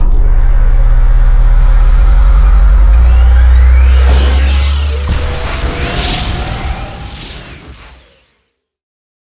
TheExperienceLight - A lightened version of the official VIP The Experience soundpack.
partlaunch.wav